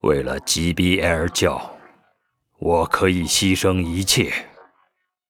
文件 文件历史 文件用途 全域文件用途 Vanjelis_tk_03.ogg （Ogg Vorbis声音文件，长度5.3秒，105 kbps，文件大小：68 KB） 源地址:地下城与勇士游戏语音 文件历史 点击某个日期/时间查看对应时刻的文件。 日期/时间 缩略图 大小 用户 备注 当前 2018年5月13日 (日) 02:57 5.3秒 （68 KB） 地下城与勇士  （ 留言 | 贡献 ） 分类:范哲利斯 分类:地下城与勇士 源地址:地下城与勇士游戏语音 您不可以覆盖此文件。